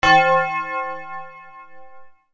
bang
Sonido FX 6 de 42
bang.mp3